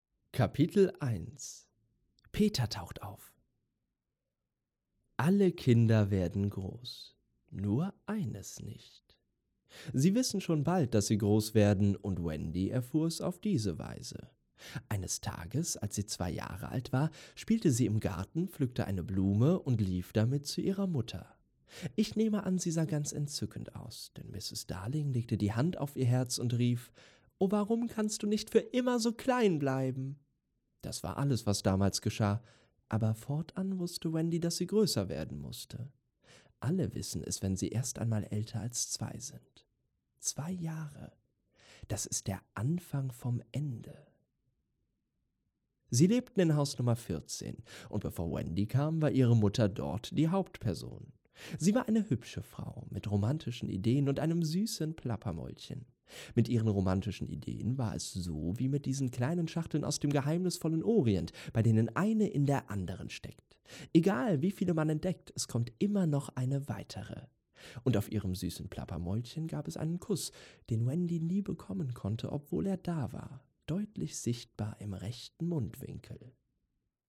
Sprechprobe: Sonstiges (Muttersprache):
Noted for his authentic, friendly & warm personas. A versatile performer with a variety of styles.